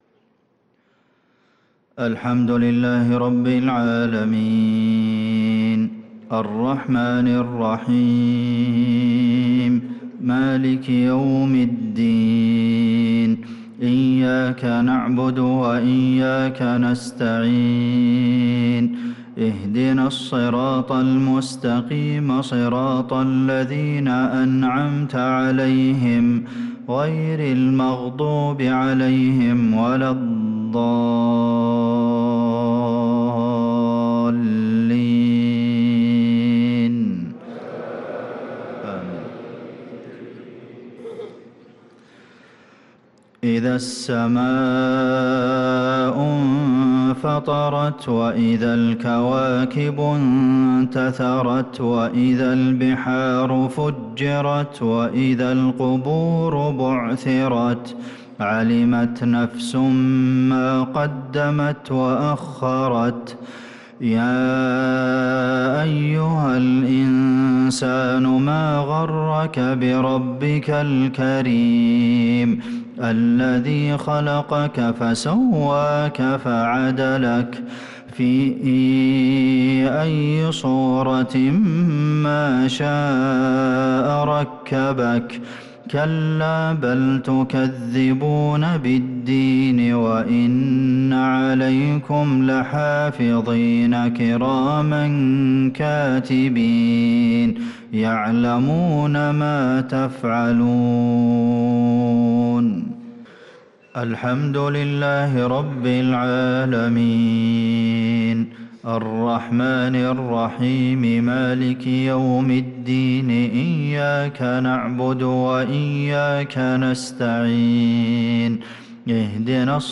صلاة المغرب للقارئ عبدالمحسن القاسم 10 شوال 1445 هـ
تِلَاوَات الْحَرَمَيْن .